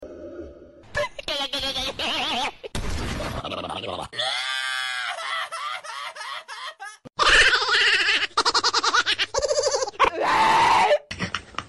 Sound Effects
Goofy Laugh Sounds